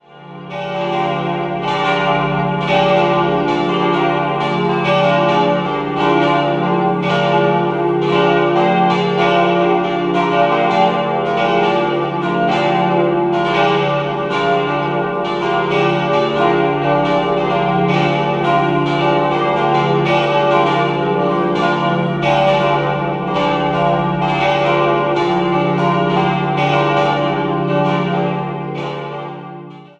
Die weithin sichtbare Pfarrkirche wurde 1767 erbaut, wobei die Turmfundamente noch aus romanischer Zeit stammen. 4-stimmiges Geläut: d'-f'-g'-a' Die Glocken 2 und 4 wurden 1708 bzw. 1712 von Franz Kern in Augsburg gegossen.